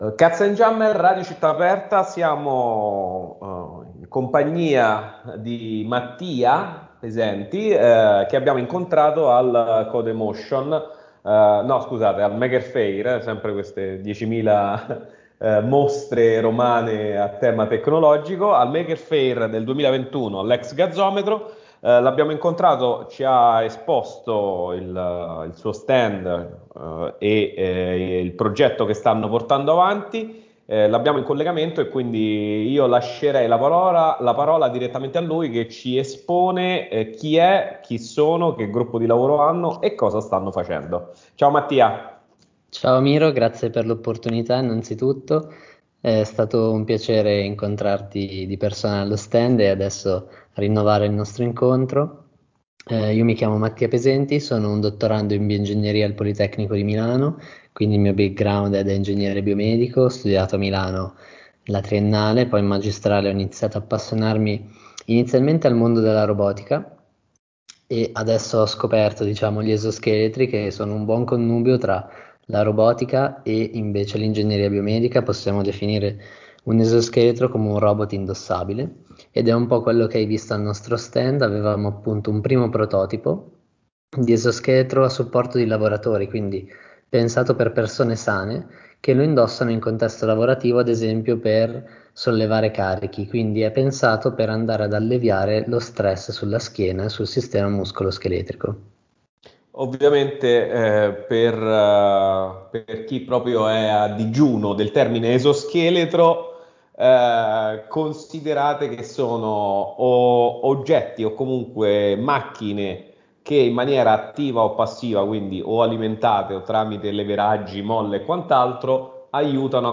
Intervista per il progetto Back-Support Exoskeleton